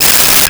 Cell Phone Ring 11
Cell Phone Ring 11.wav